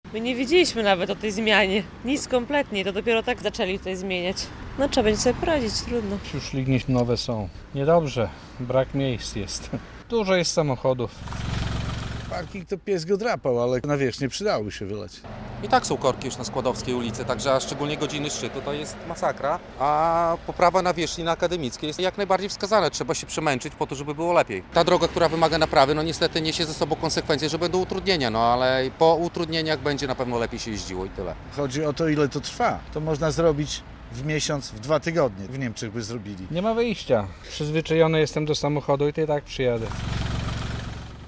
Mieszkańcy o remoncie ul. Akademickiej - relacja